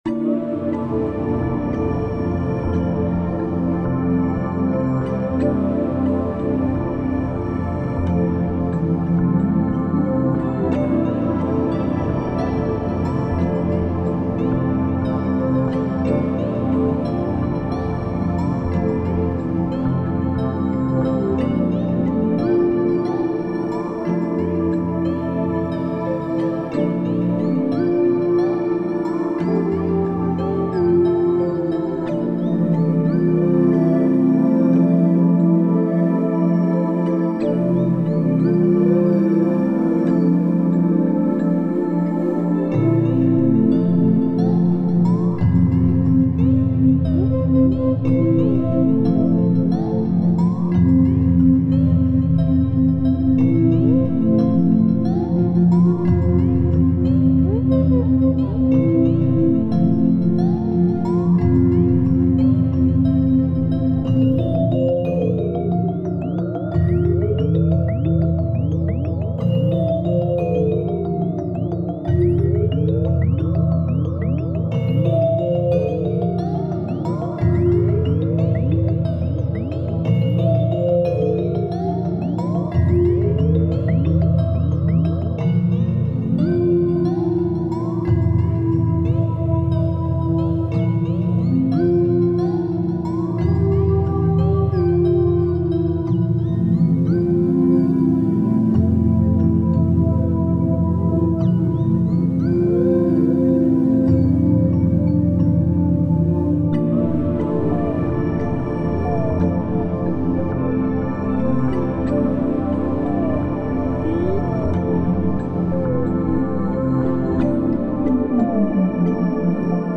タグ: フィールド楽曲 リラックス/睡眠 幻想的 海/水辺 コメント: クラゲが漂っている穏やかな海をイメージした楽曲。